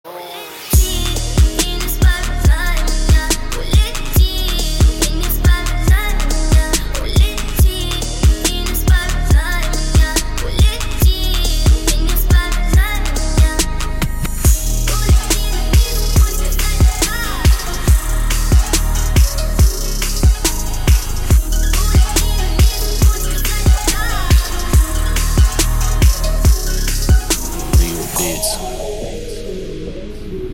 • Качество: 128, Stereo
Trap
Bass